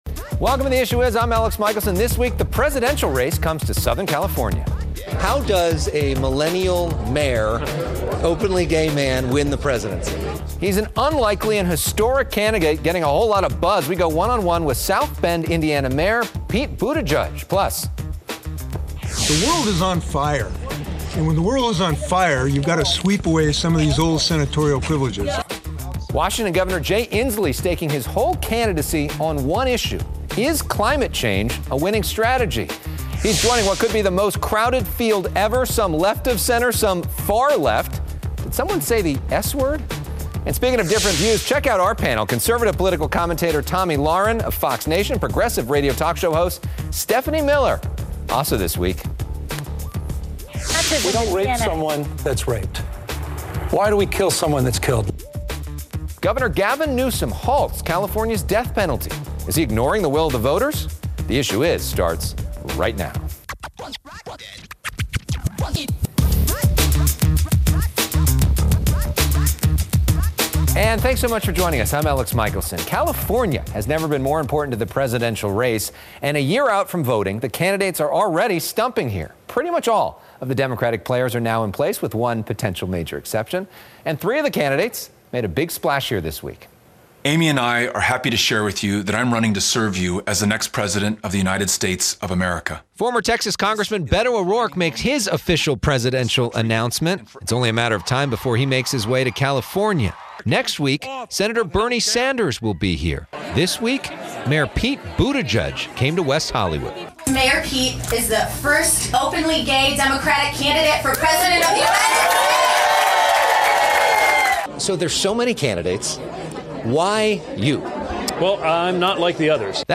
Two strong women with two very different views.
Stephanie Miller is a progressive radio talk show host, author, and comedian. They debate the 2020 race, the President's national emergency, Governor Newsom's decision to end the death penalty, and the college admissions scandal.